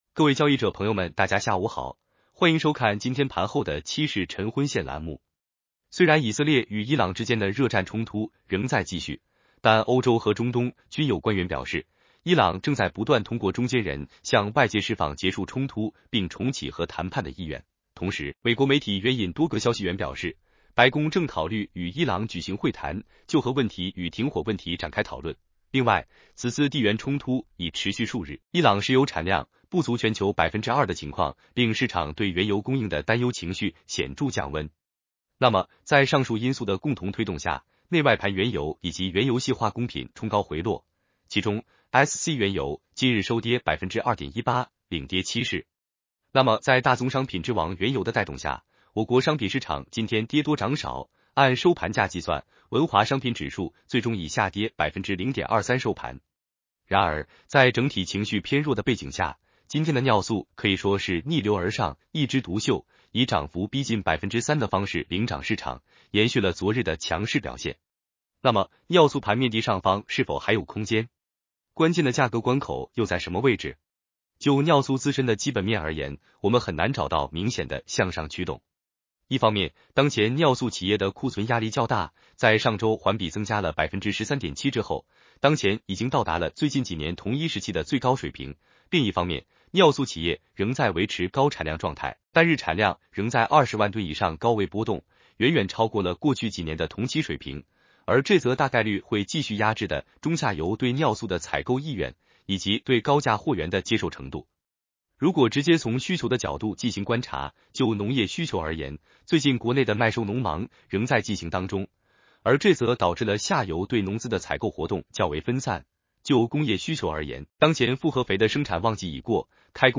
男生普通话版 下载mp3